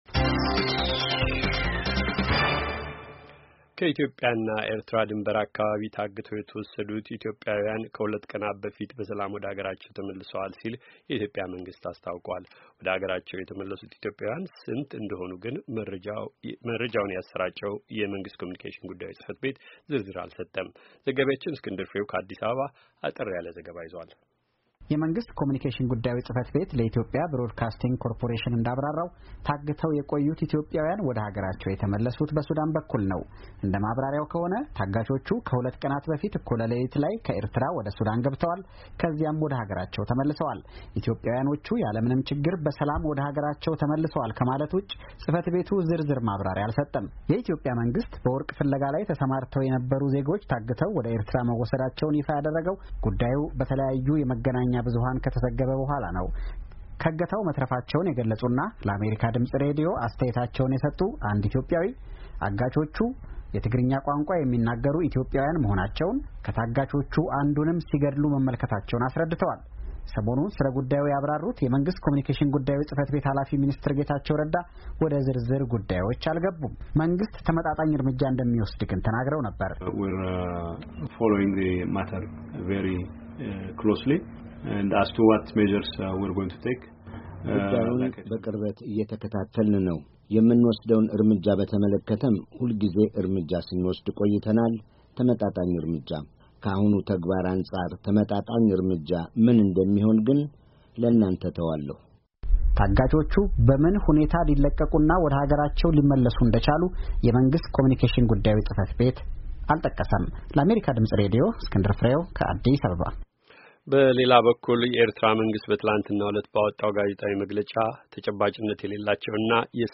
ከአዲስ አበባ አጠር ያለ ዘገባ ልኳል።